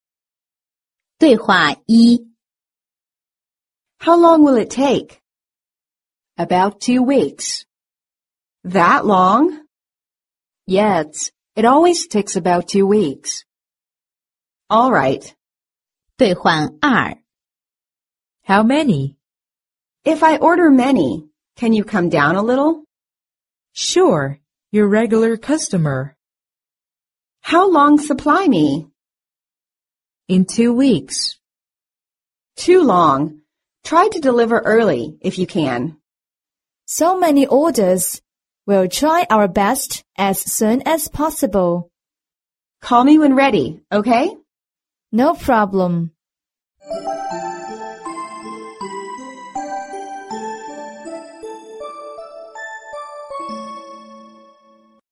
外贸服装口语 第46期:交货日期之情景对话 听力文件下载—在线英语听力室